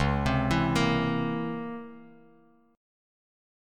C#mM13 chord